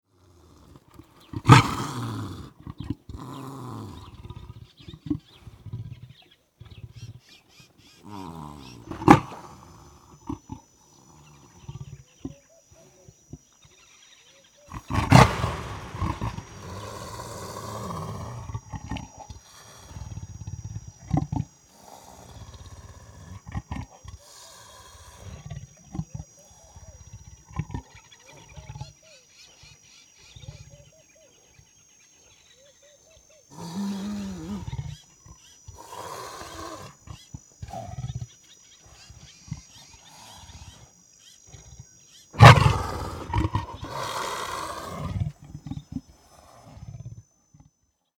0402_Schwarzwild_Keiler - Heintges Lehr- und Lernsystem GmbH
Schwarzwild-Keiler.mp3